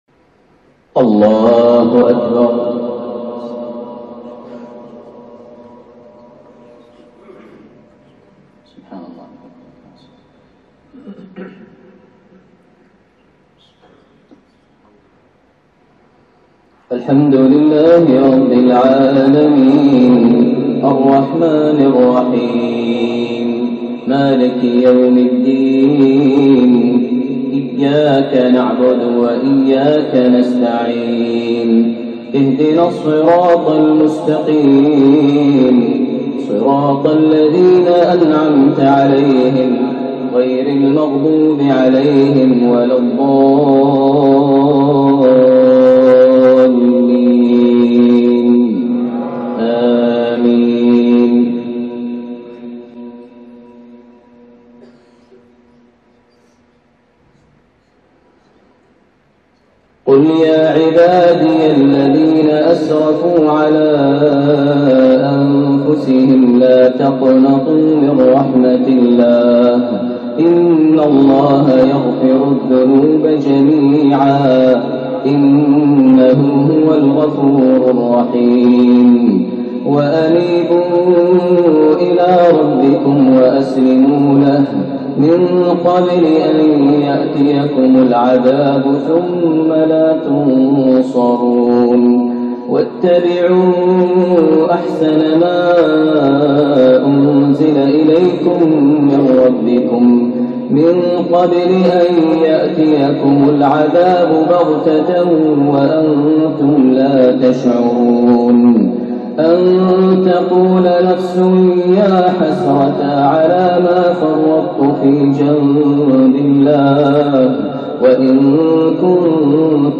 صلاة العشاء في قاعدة الملك عبدالله الجوية لتكريم حفظة القرآن من سورة الزمر عام ١٤٣٥هـ > إمامة الشيخ ماهر المعيقلي وجهوده الدعوية داخل السعودية > المزيد - تلاوات ماهر المعيقلي